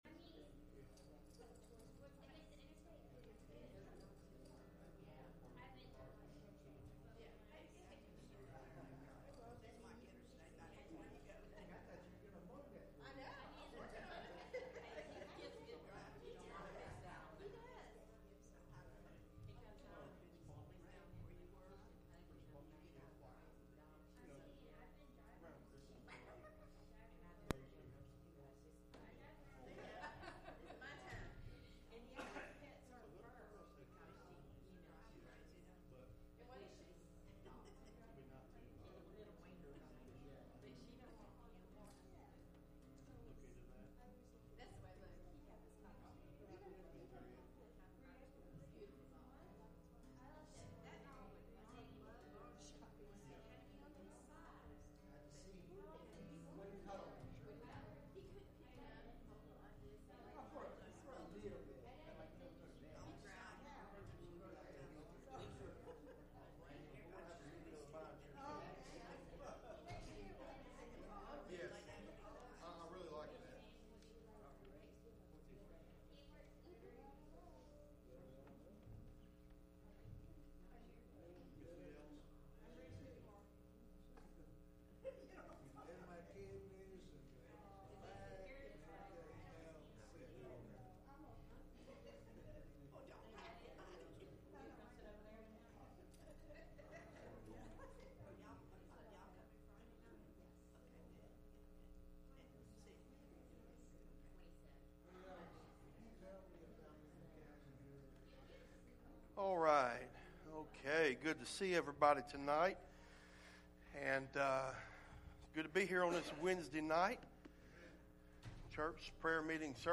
Service Type: Midweek Meeting